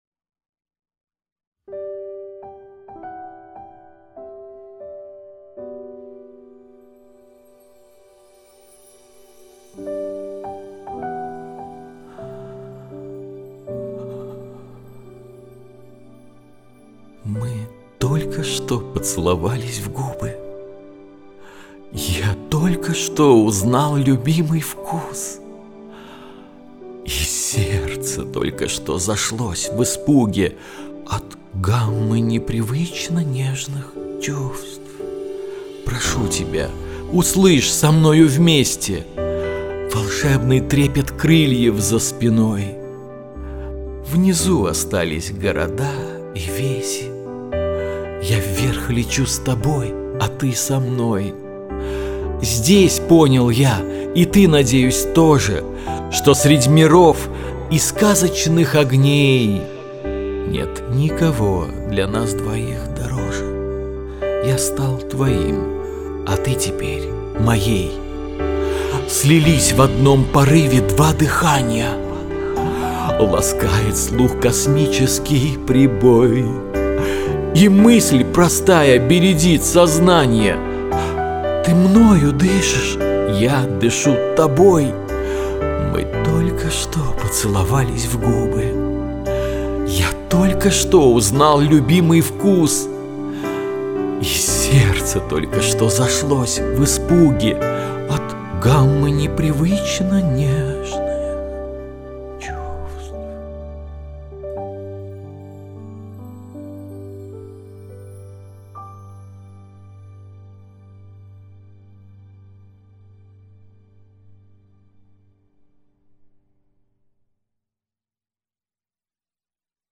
Авторское аудиопрочтение "Поцелуй" (прошу совета)
Обрабатывал без фанатизма (малость подкомпрессил, малость эквализнул, добавил немного пространства).
В принципе, я с минусом ничего не делал, только слегка увеличил стереобазу. Записывалось в домашних условиях, поэтому интересует наличие бубнящих/резонансов.